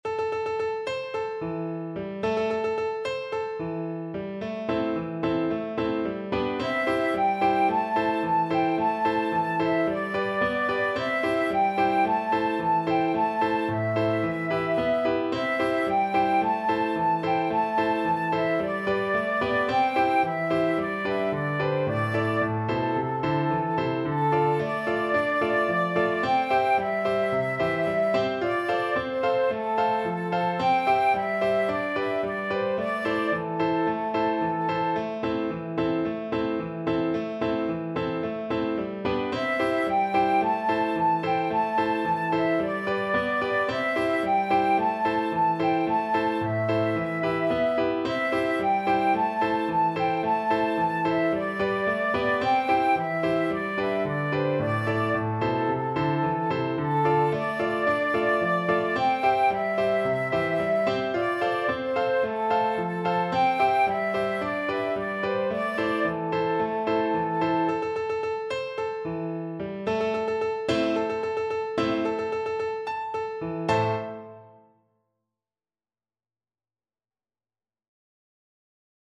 Flute
A minor (Sounding Pitch) (View more A minor Music for Flute )
Allegro =c.110 (View more music marked Allegro)
4/4 (View more 4/4 Music)
Traditional (View more Traditional Flute Music)
world (View more world Flute Music)
Chinese
kangding_FL.mp3